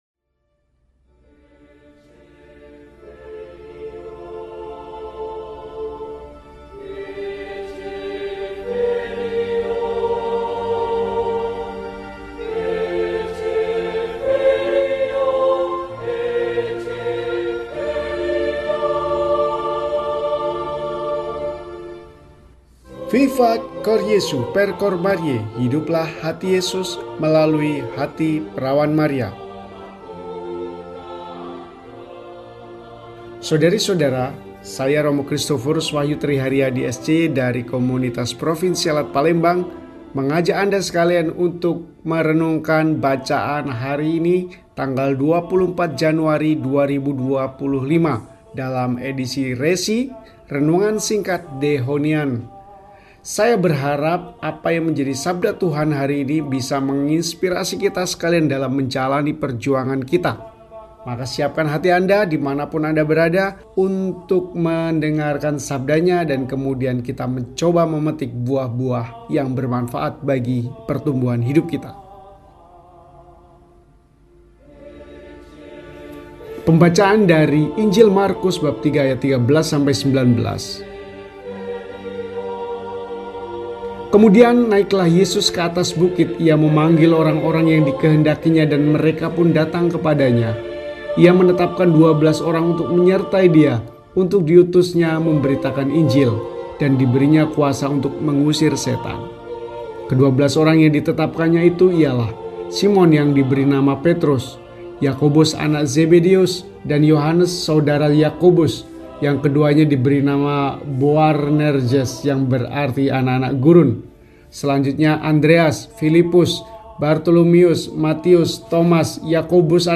Jumat, 24 Januari 2025 – Peringatan Wajib St. Fransiskus de Sales, Uskup dan Pujangga Gereja (Hari Ketujuh Pekan Doa Sedunia untuk Persatuan Umat Kristen) – RESI (Renungan Singkat) DEHONIAN